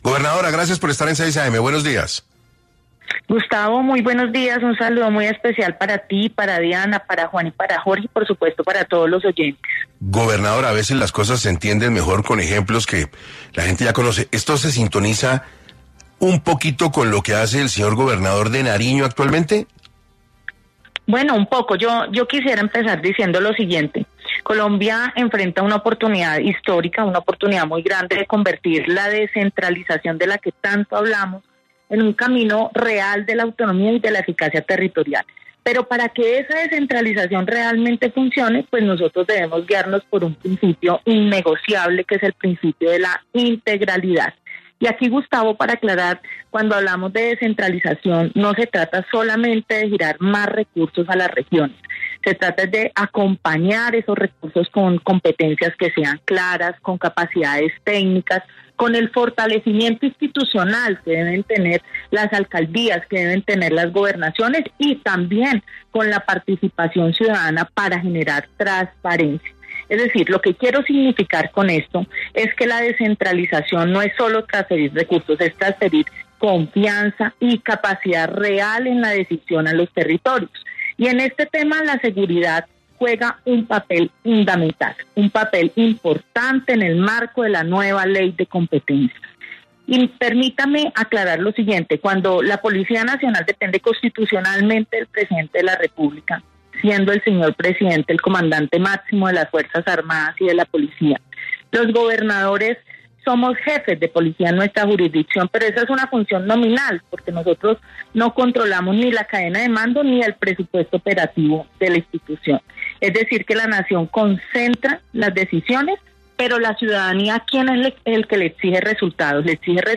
Adriana Magali Matiz, gobernadora del Tolima habló en 6AM de Caracol Radio sobre la propuesta de autonomía para que los gobernadores puedan iniciar diálogos de paz.